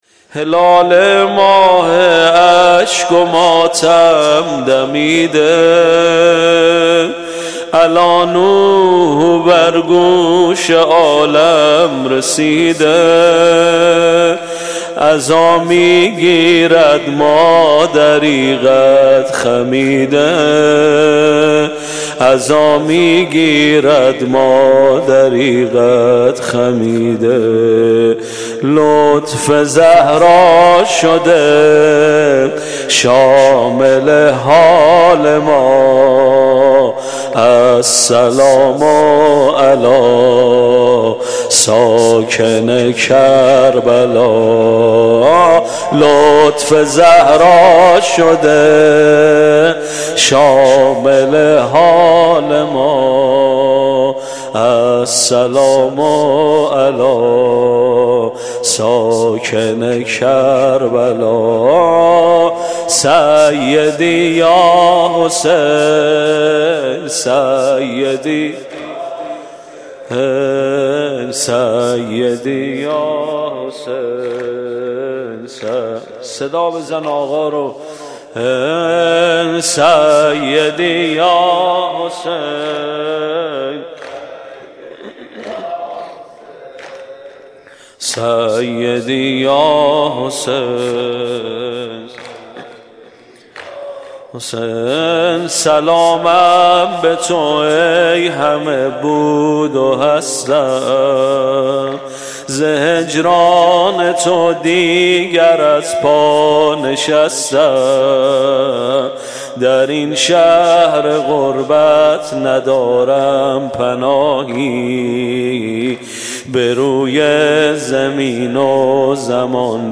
مداحی شب اول محرم ۹۹ مدرسه فیضیه - هیئت ثارالله قم
سینه زنی